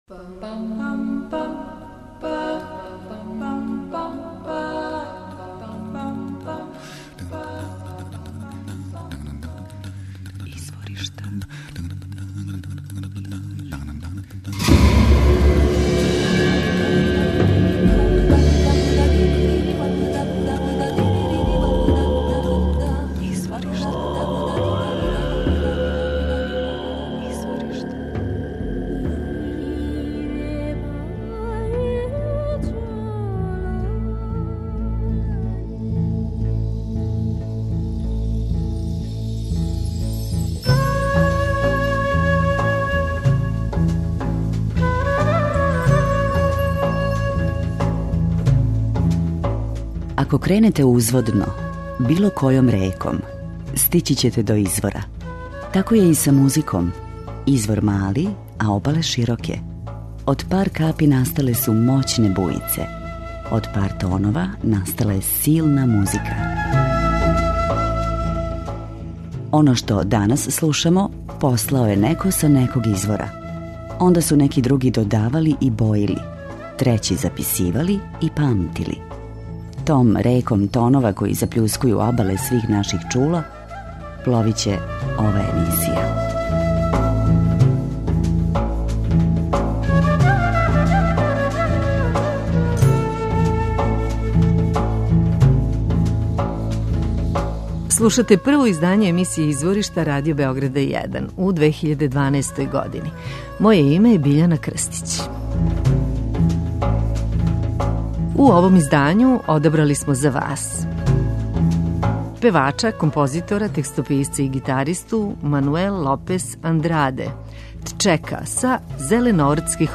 Веран традицији, али у исто време и савремен, користи традиционалне музичке форме morna, coladeira , funaná, као и западноафричке ритмове.